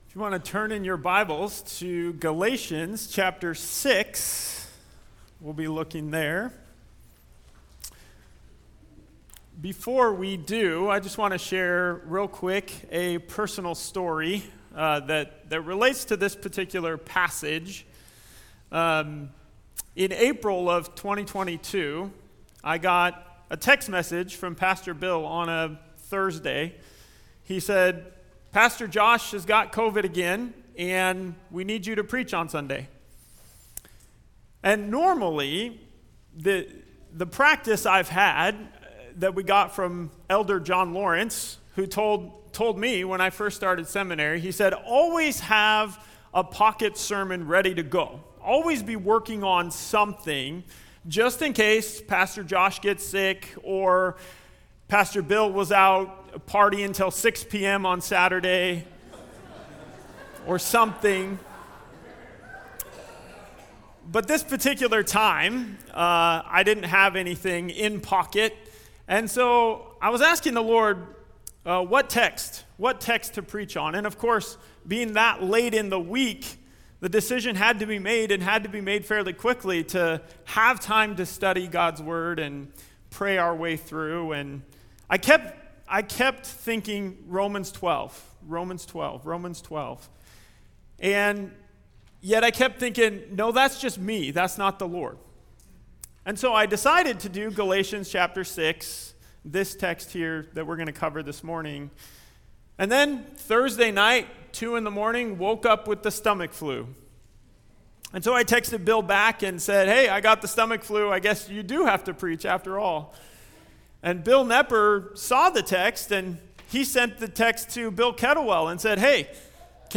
Guest Speaker (Galatians 6:1-5)
Topical Message